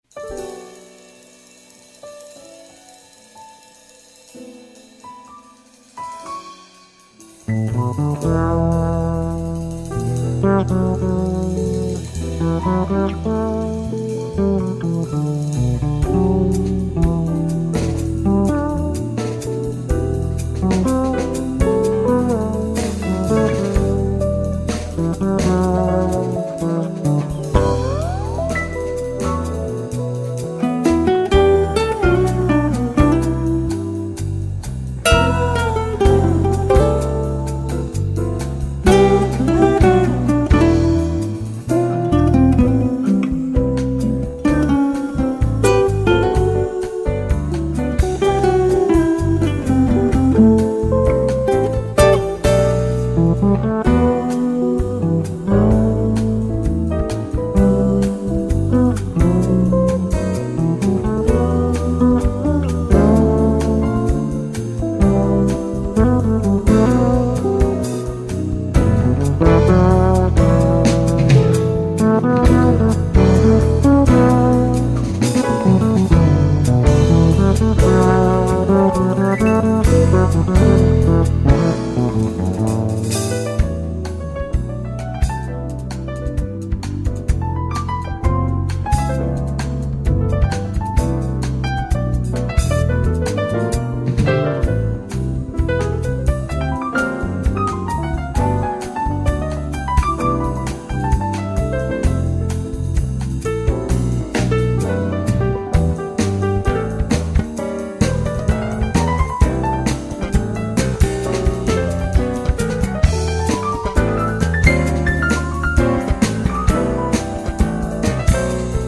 276   03:59:00   Faixa:     Jazz
Bateria